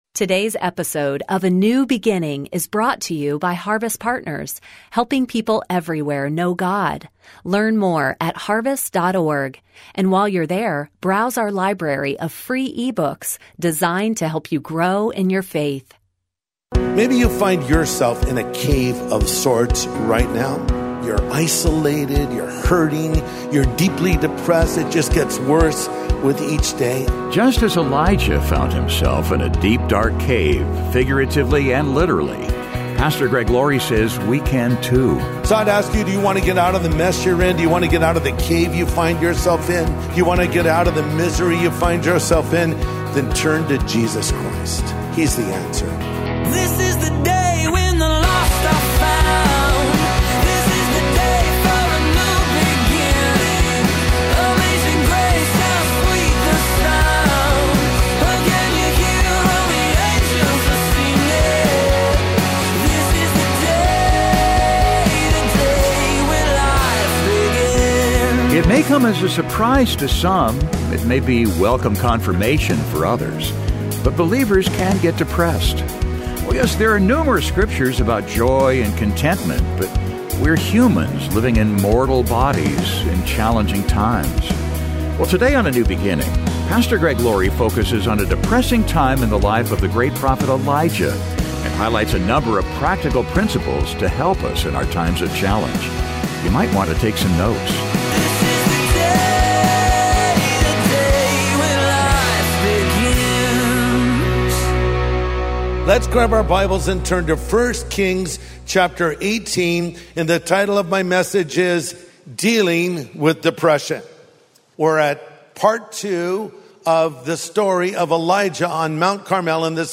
Well today on A NEW BEGINNING, Pastor Greg Laurie focuses on a depressing time in the life of the great prophet Elijah, and highlights a number of practical principles to help us in our times of challenge.